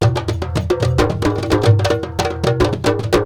PERC 10.AI.wav